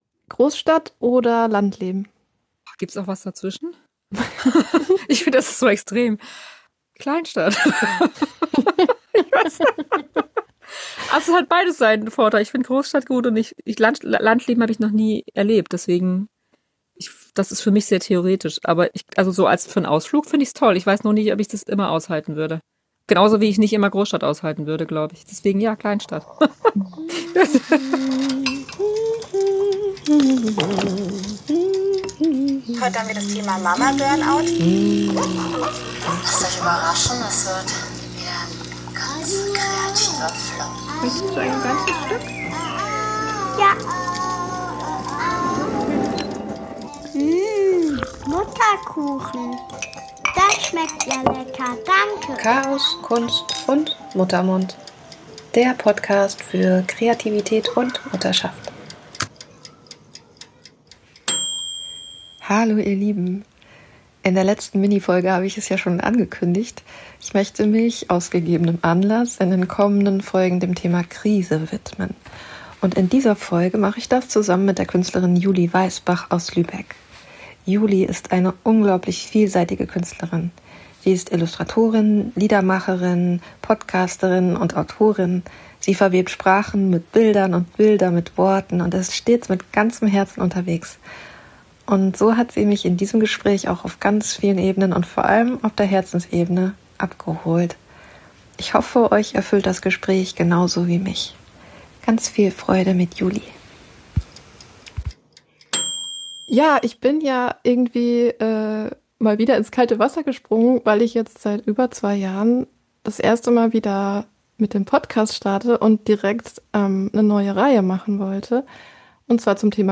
Ich hoffe, Euch erfüllt das Gespräch genauso wie mich!